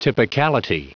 Prononciation du mot typicality en anglais (fichier audio)
Prononciation du mot : typicality